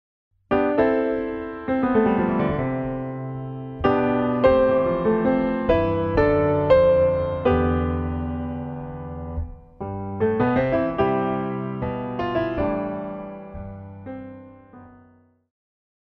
古典,流行
小提琴
钢琴
演奏曲
世界音乐
仅伴奏
没有主奏
没有节拍器